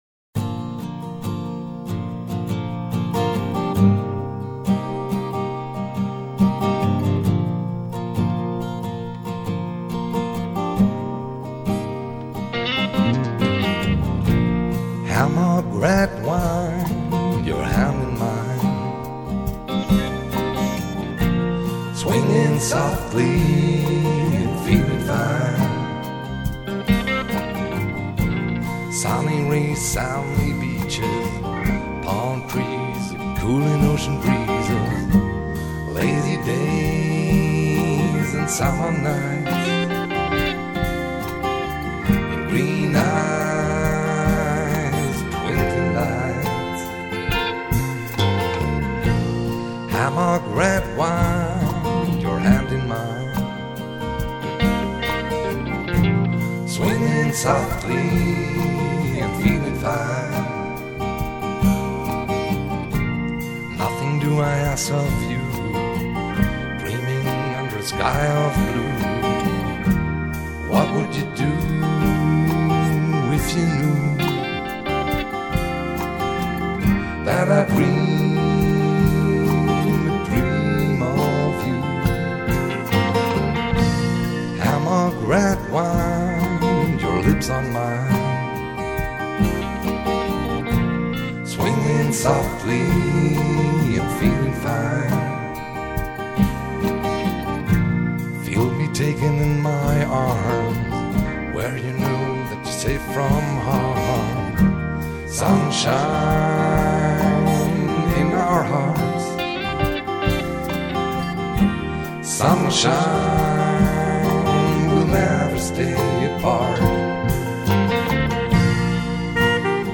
Tenor Sax.